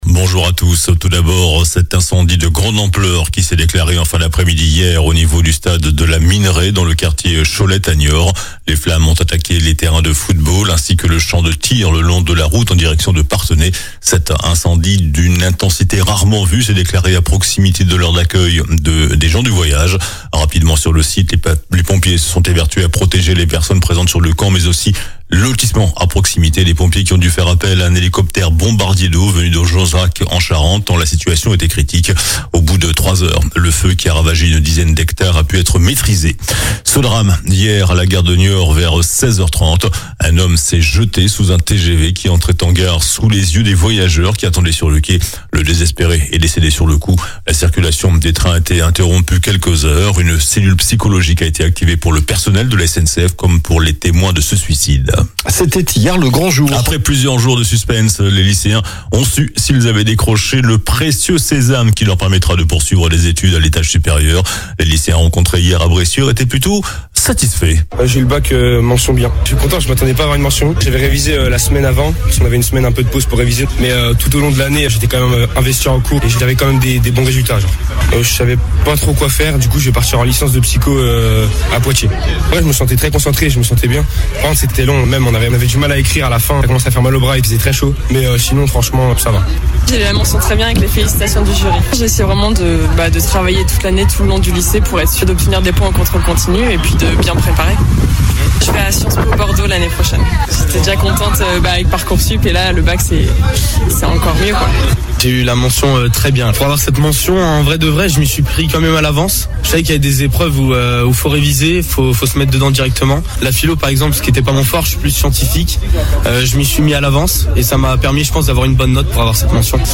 JOURNAL DU SAMEDI 05 JUILLET